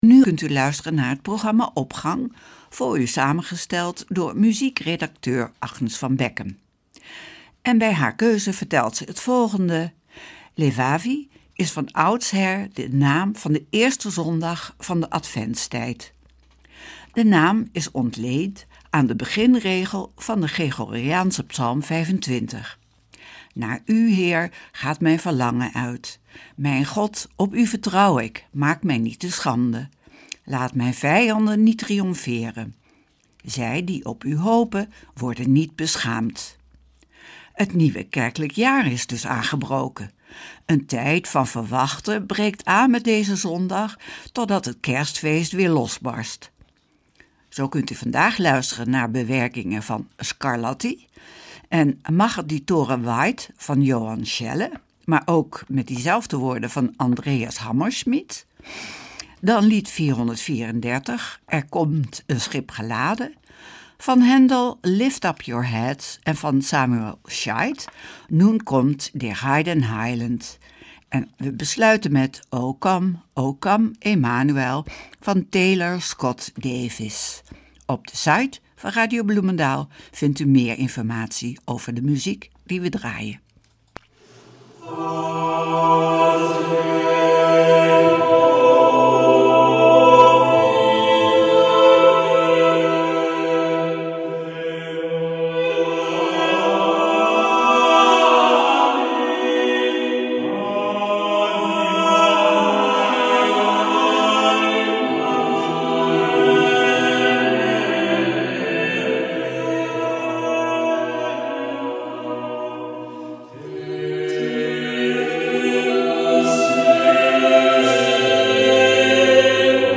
Opening van deze zondag met muziek, rechtstreeks vanuit onze studio.